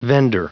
Prononciation du mot vender en anglais (fichier audio)
Prononciation du mot : vender